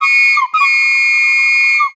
ihob/Assets/Extensions/CartoonGamesSoundEffects/Train_v1/Train_v4_wav.wav at master
Train_v4_wav.wav